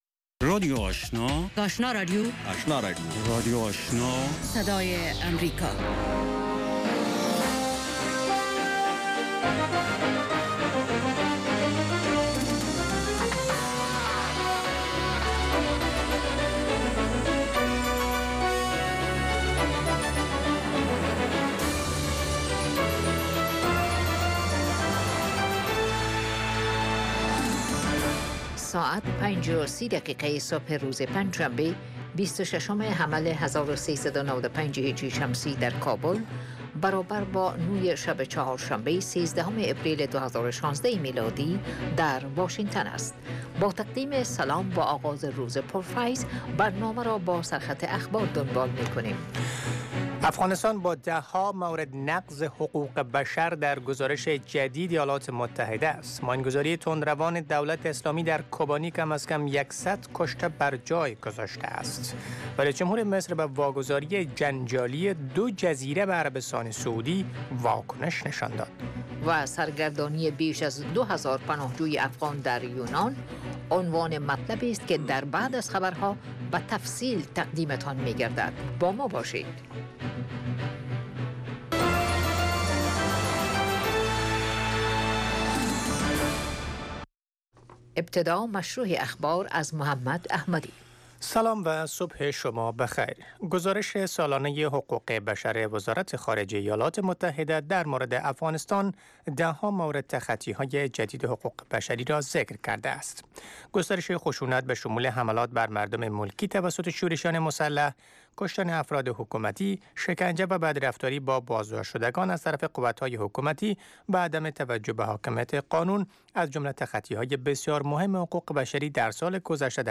اولین برنامه خبری صبح، حاوى تازه ترين خبرهاى افغانستان و جهان است. این برنامه، همچنین شامل گزارش هایی از افغانستان، ایالات متحده امریکا و مطلب مهمی از جهان و تبصره حکومت ایالات متحده است.